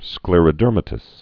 (sklîrə-dûrmə-təs)